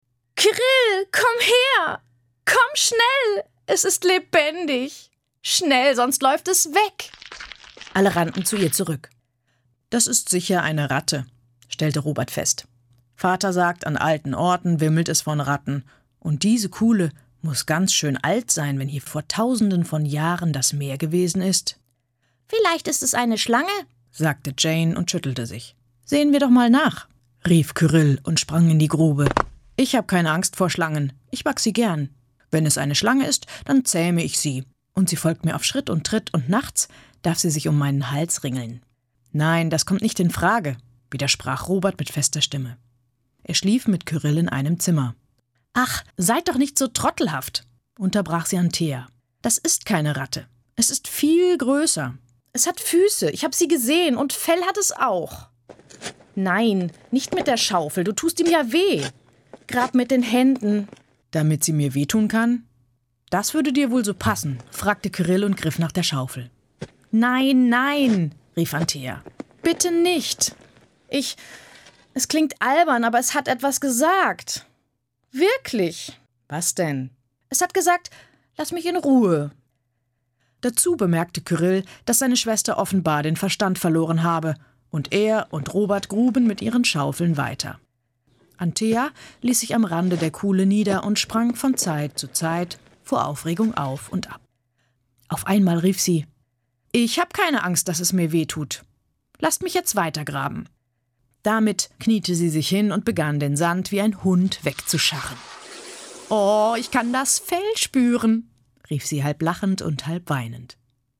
Stimmalter: 20 – 40 Stimmfarbe: Alt – Mezzo Anmutung: warm, seriös, erotisch, präsent, keck, jung oder älter, dynamisch, erzählend